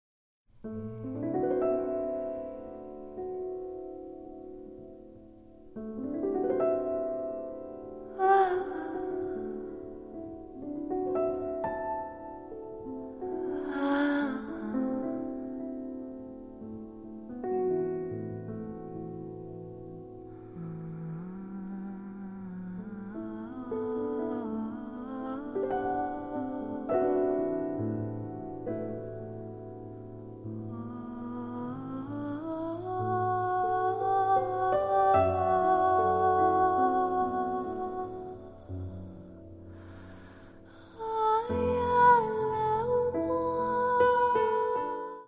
voice
piano
accordion